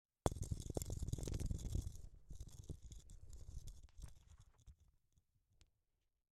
金属振动声音
描述：电缆敲击柱子（金属声音）。放慢了低沉的声音（减慢了400％）。
标签： 争执 麦克风 金属 岗位 颤动 伊利诺伊 持续音 低音的 声音 振动
声道单声道